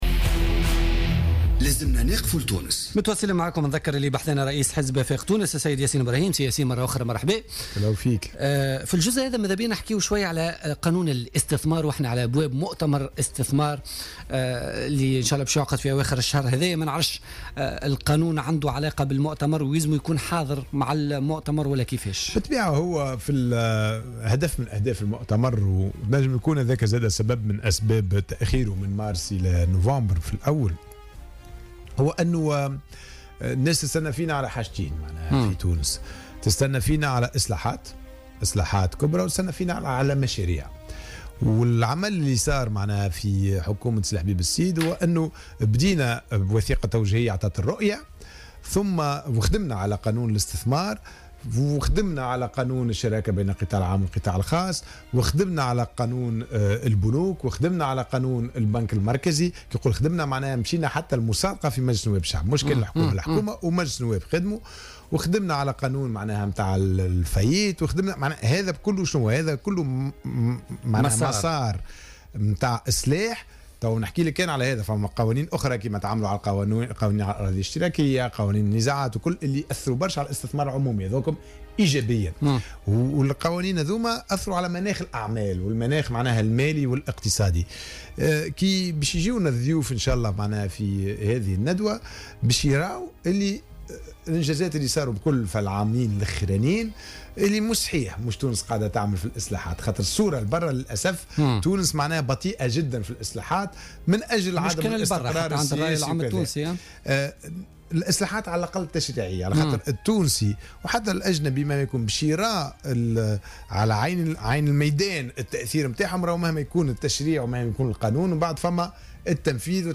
Le président du parti Afek Tounes, Yassine Ibrahim, était l'invité